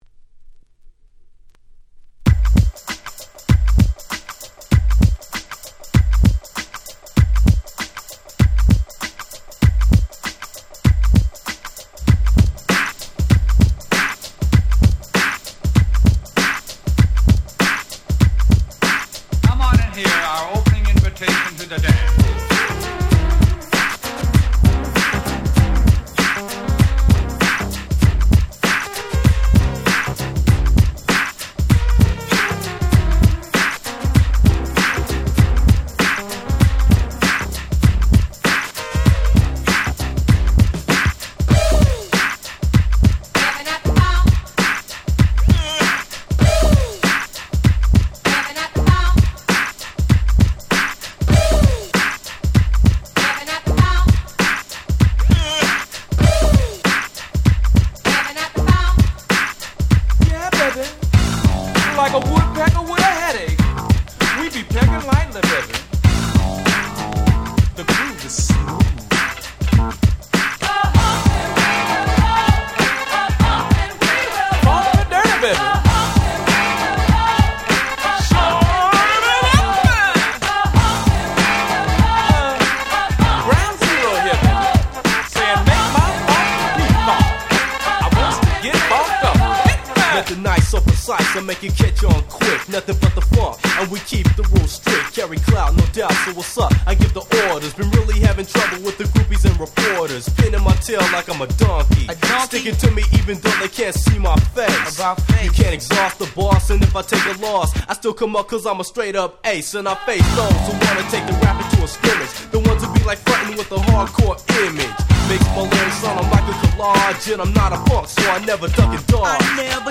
91' Smash Hit West Coast Hip Hop !!
グラウンドゼロ 90's ウエスト ウエッサイ Boom Bap ブーンバップ